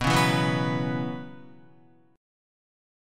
Bsus2sus4 chord